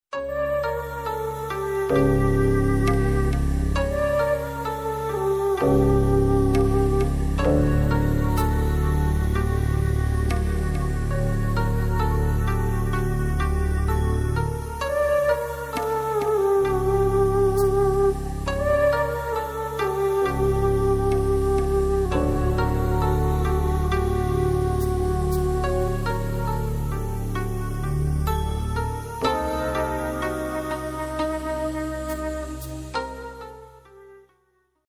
C'est un peu dans le genre "Warszawa" sauf que c'est pas ça!